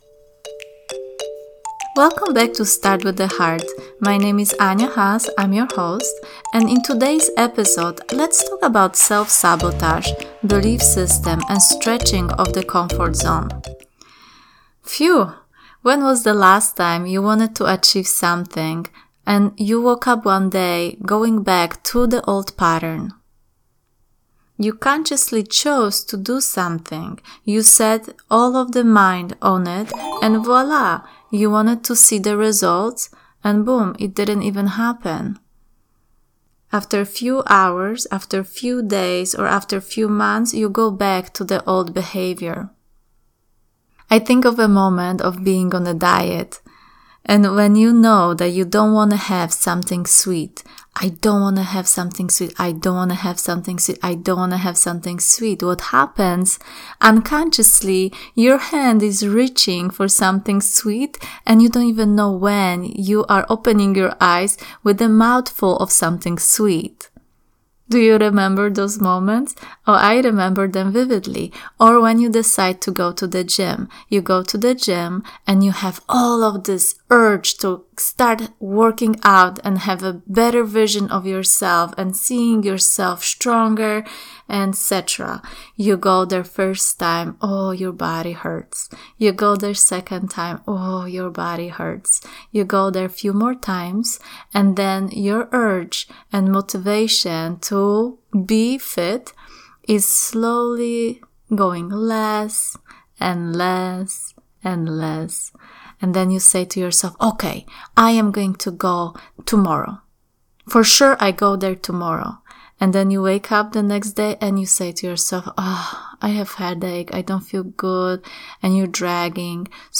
Listen to guided visualization to help you rise vibration and get closer to the person you want to be.